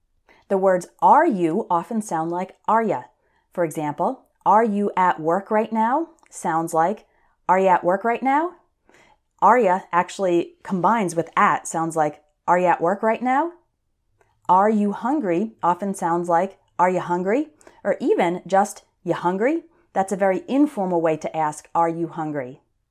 One reason for the difficulty is that when native English speakers are talking fast, we often change how we pronounce the words at the beginning of questions.
Are You >> Arya